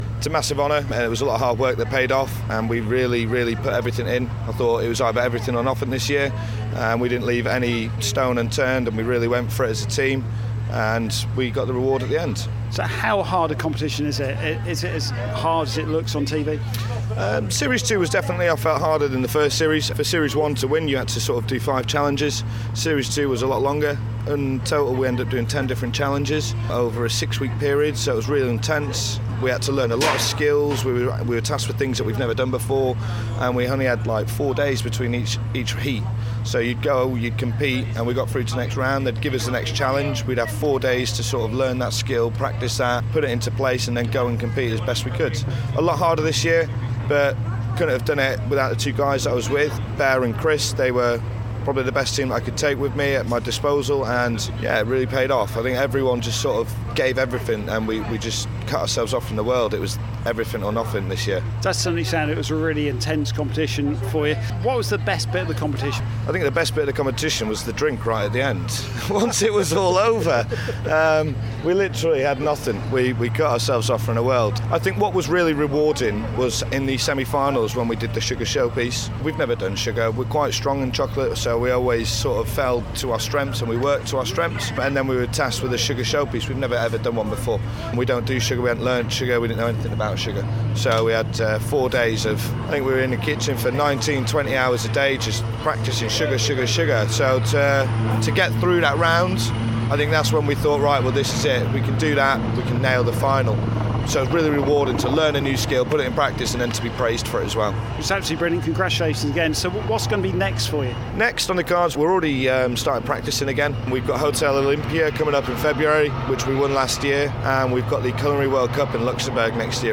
caught up with one of the Chefs at the recent RAF Odiham Families day